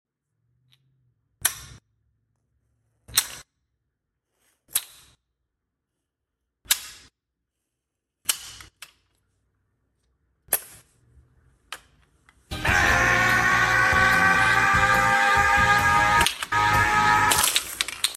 Dragon Ball Z Goku Megabot sound effects free download
Dragon Ball Z Goku Megabot Click ASMR